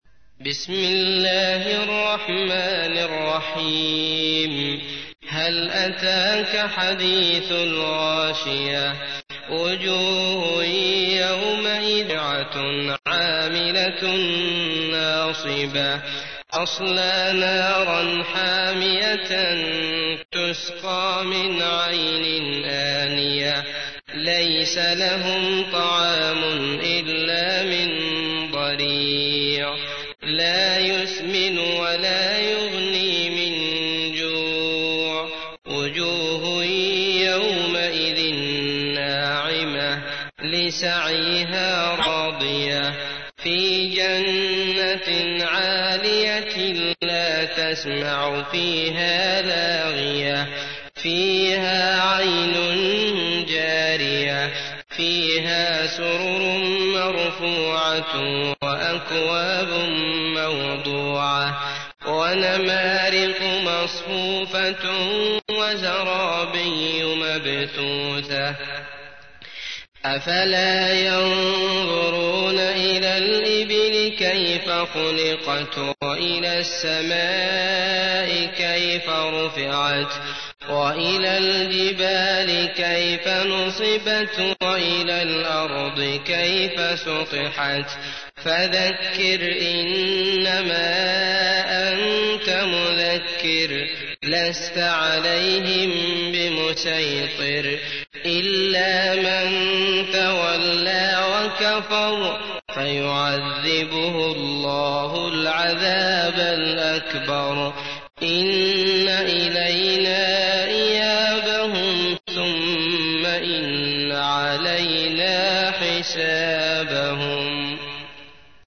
تحميل : 88. سورة الغاشية / القارئ عبد الله المطرود / القرآن الكريم / موقع يا حسين